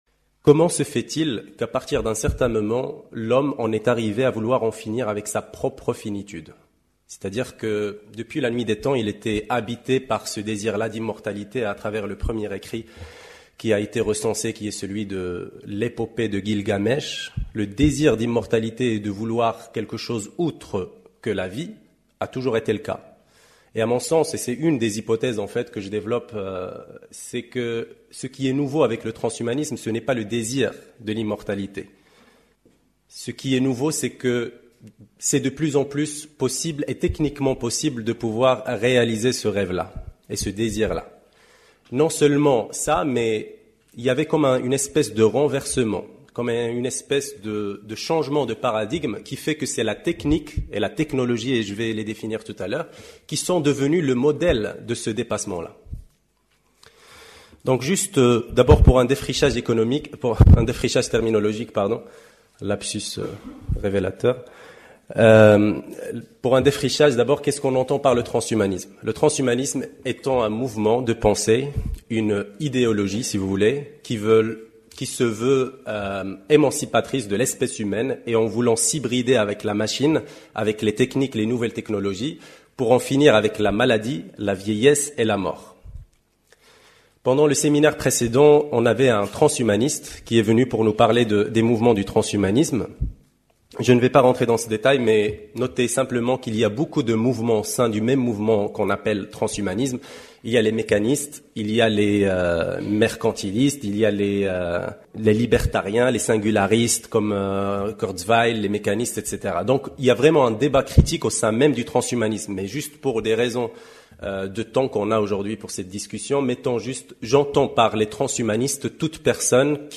Dans un contexte d'intérêt croissant et de positionnements contrastés sur le transhumanisme, un cycle de séminaires plurisdisciplinaires est organisé par le Pôle Risques avec comme objectif de réaliser un tour d'horizon des questions posées par cette nouvelle vague de pensée qui questionne à coeur les rapports de l'homme et de la technique.